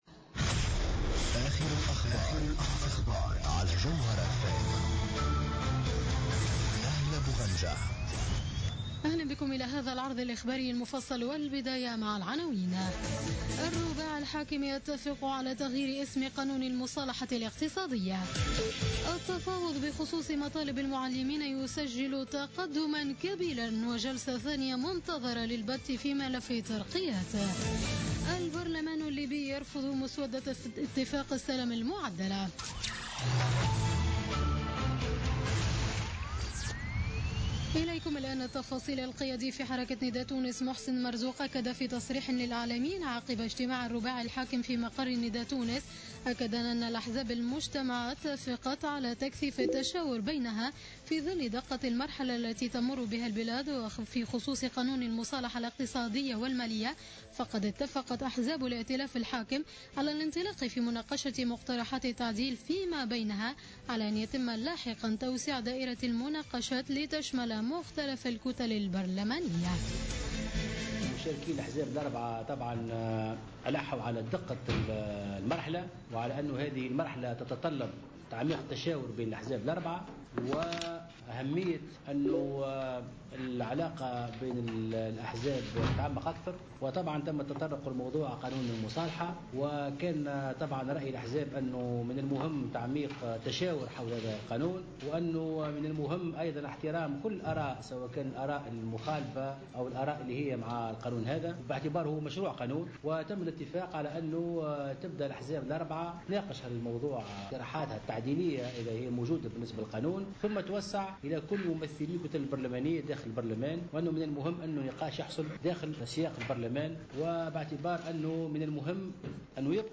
نشرة أخبار منتصف الليل ليوم الاربعاء 16 سبتمبر 2015